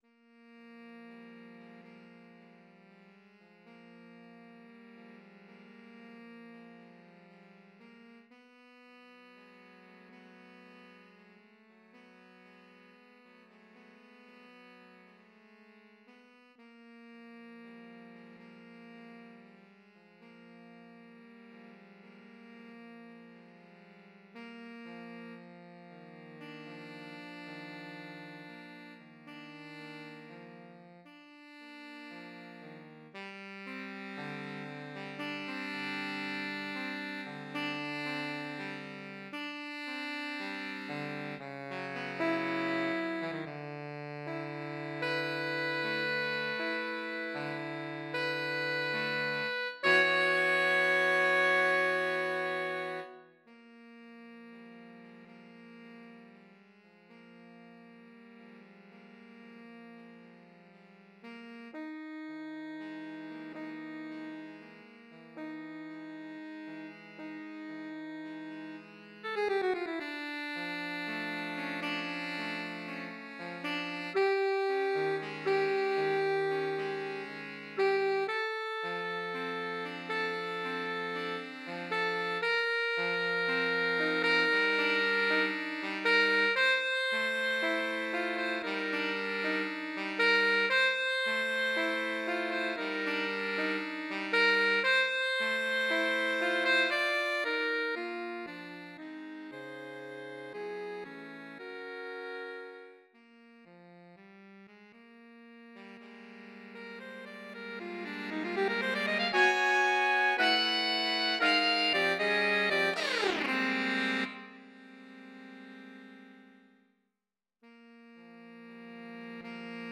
Voicing: Saxophone Quartet